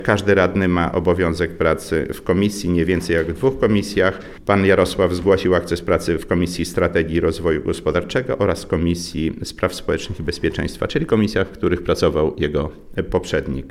O szczegółach mówi Zdzisław Przełomiec, przewodniczący Rady Miejskiej w Suwałkach.